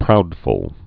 (proudfəl)